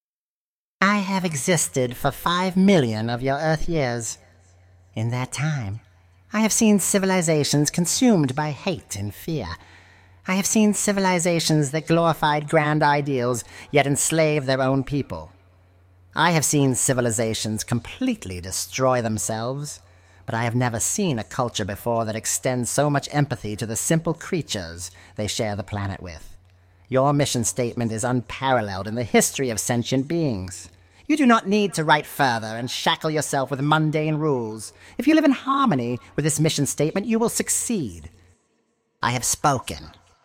She spoke in words that seemed to come from all directions. And when she spoke, it was with authority, grandeur and infinite understanding.